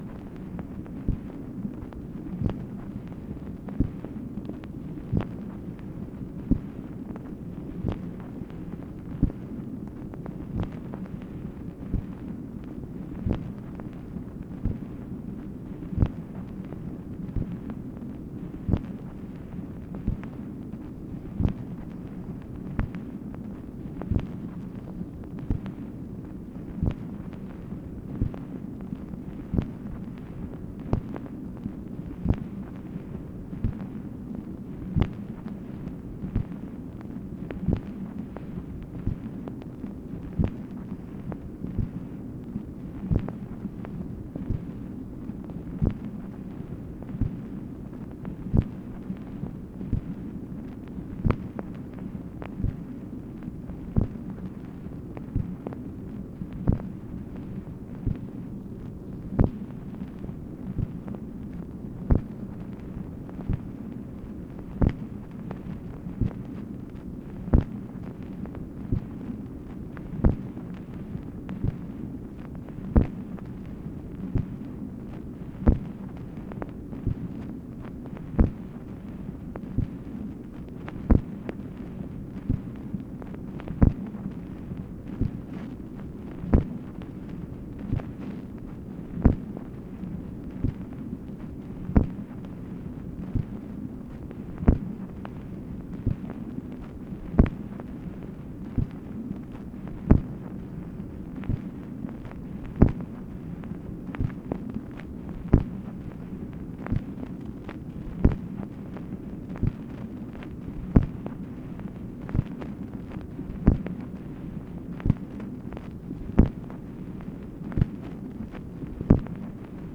MACHINE NOISE, February 7, 1964